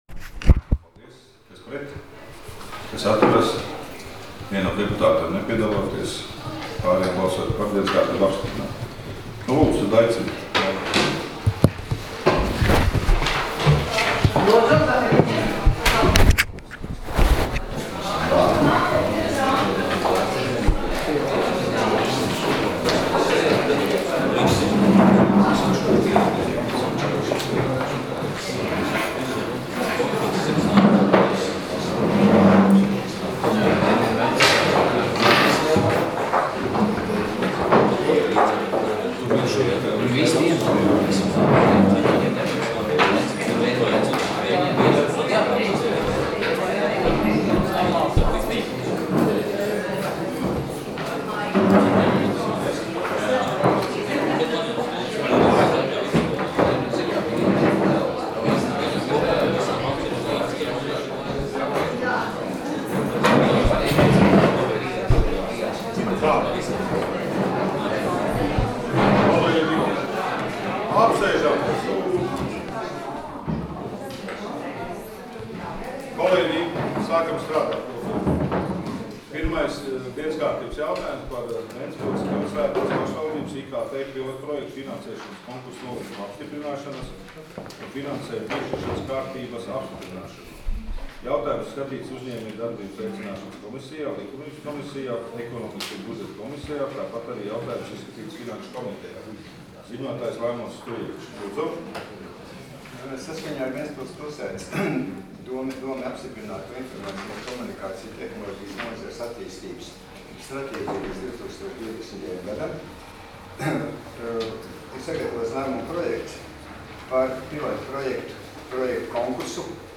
Domes sēdes 01.07.2016. audioieraksts